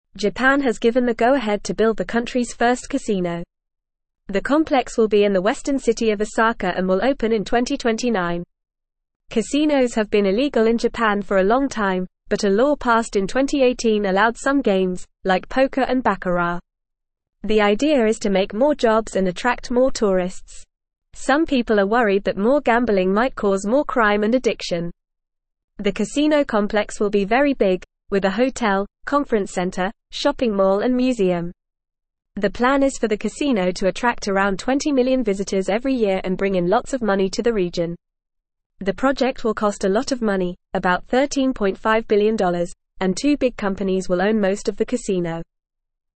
Fast
English-Newsroom-Beginner-FAST-Reading-Japan-Building-First-Casino-to-Attract-Tourists.mp3